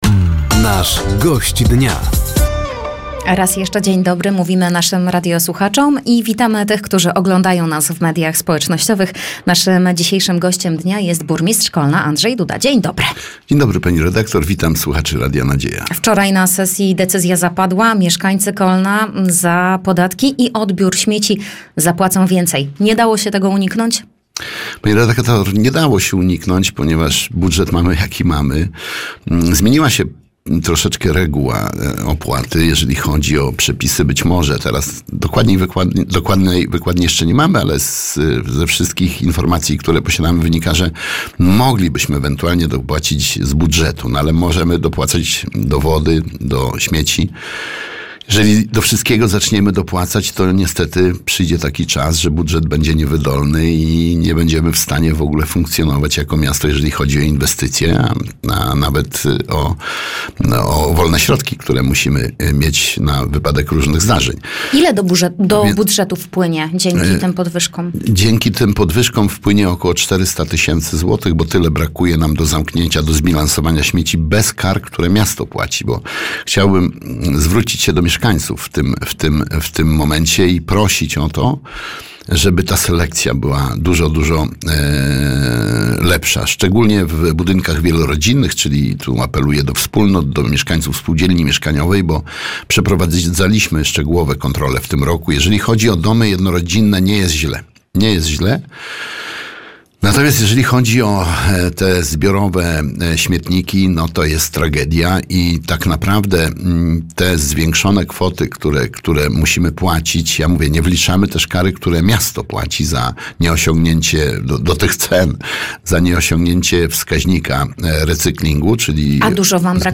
Zapraszamy do wysłuchania rozmowy z burmistrzem Kolna, Andrzejem Dudą.